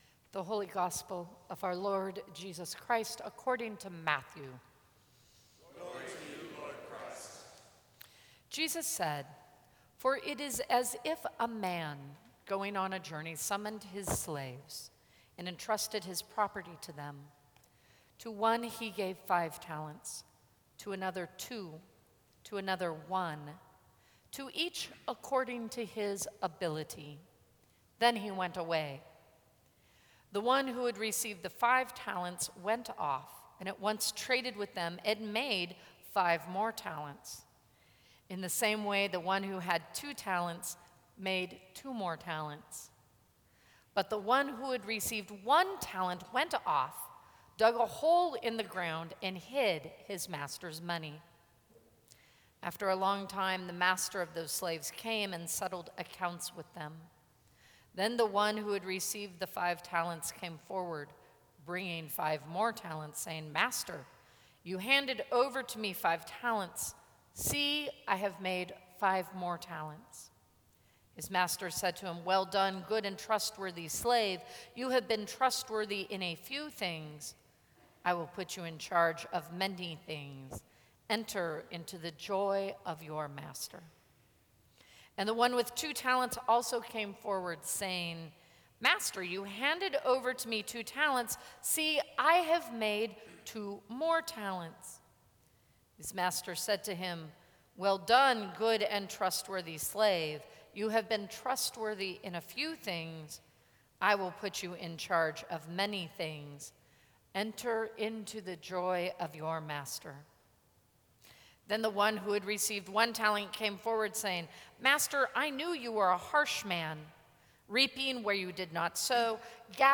Sermons from St. Cross Episcopal Church November 16, 2014.